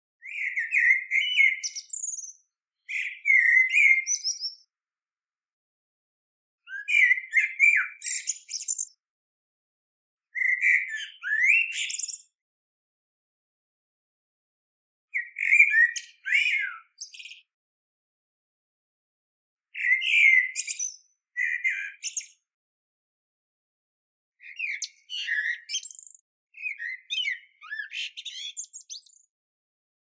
Chant du Merle
• Son : Chant du Merle
Satellitebox_Blackbird_soundfile_30s.mp3